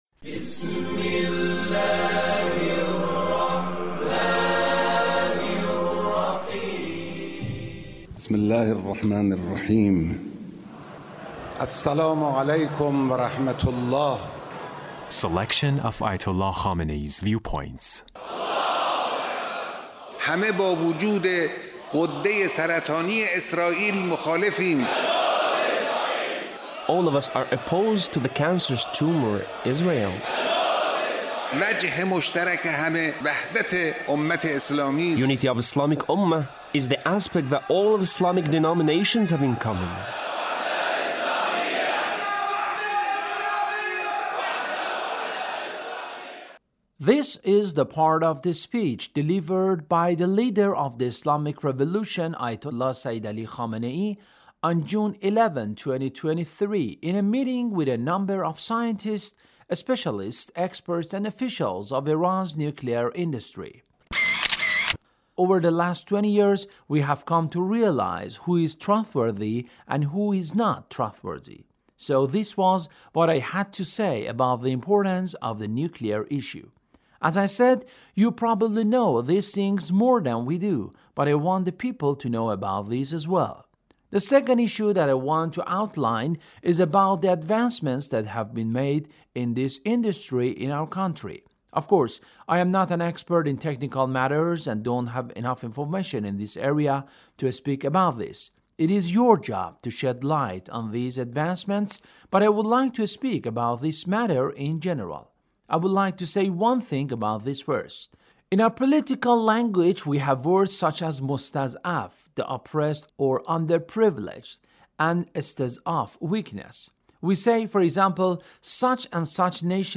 Leader's Speech in a meeting with a number of scientists,and officials of Iran’s nuclear industry